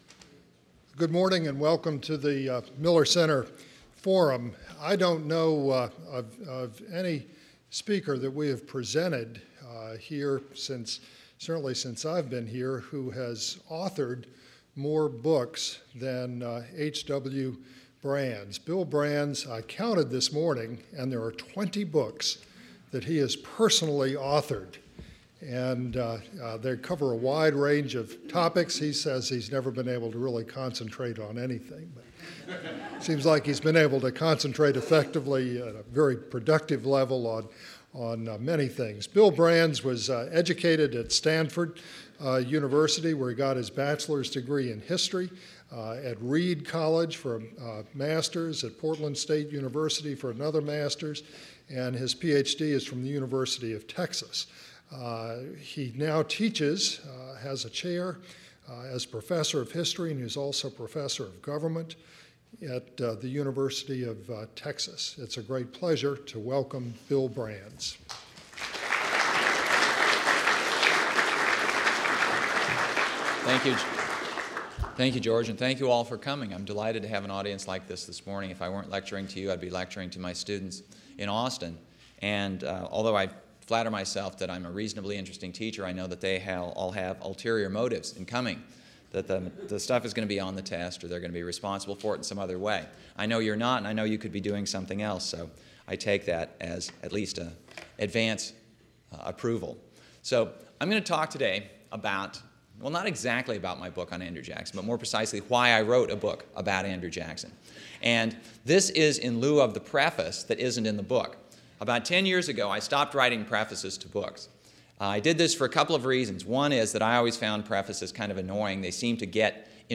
University of Texas history professor H. W. Brands examines the career of the man who won the popular vote for president in 1824 only to lose the election to John Quincy Adams, sparking electoral reforms that helped him win the 1828 election by a large margin. A book signing will follow the Forum.